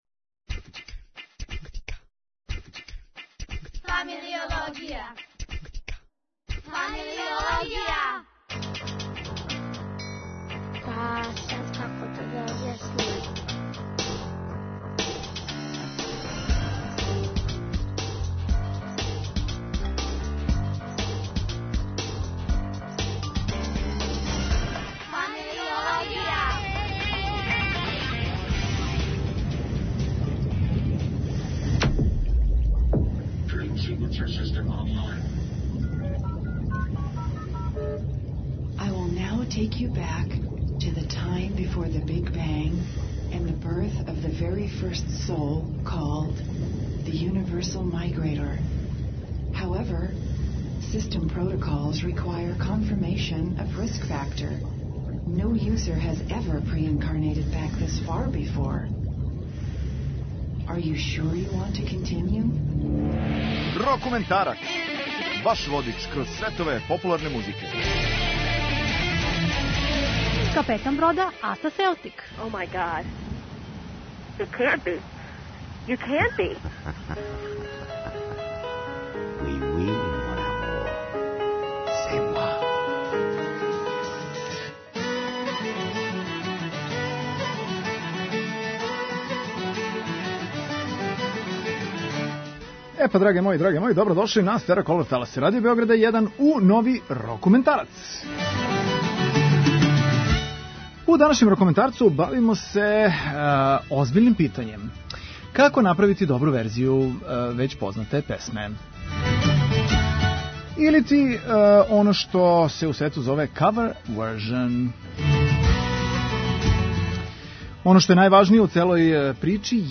О томе говоримо у данашњем Рокументарцу, слушамо добре цовер верзије великих Рок песама.